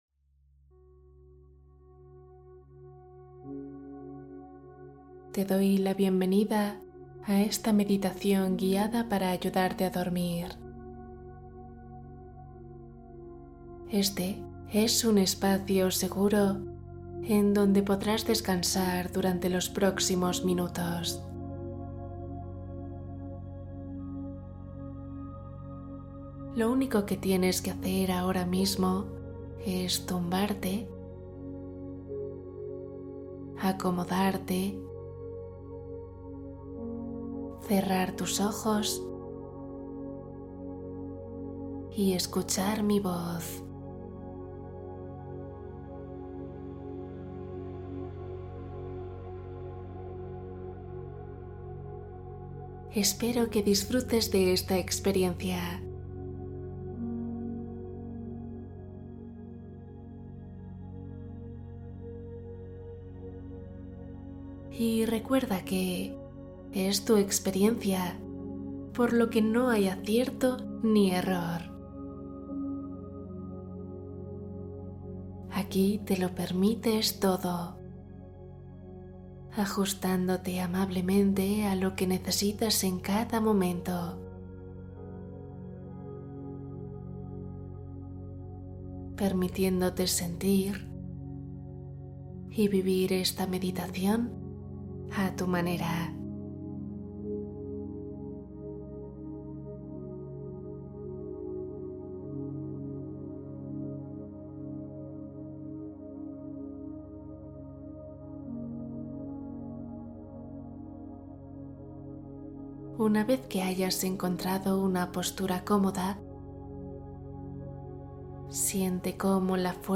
Calma tu mente Meditación guiada para dormir y soltar tensión